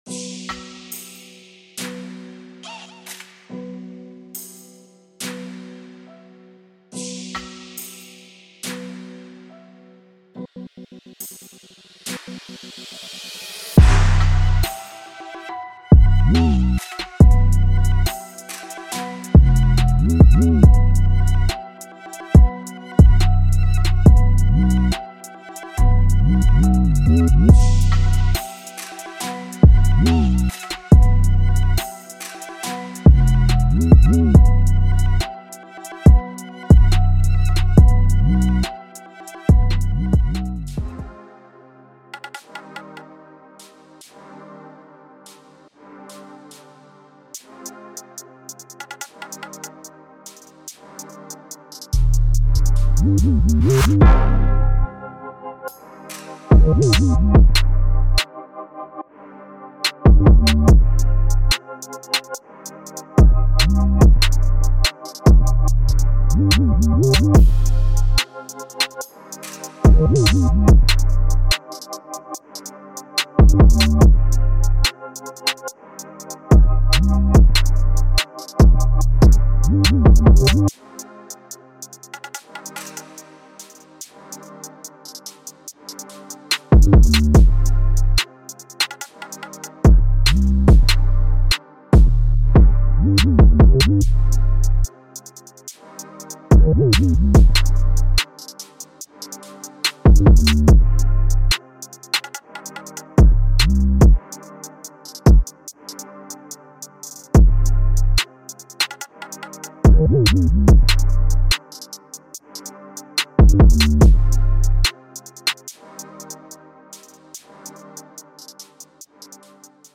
• Mini Construction Kit
• Includes Drums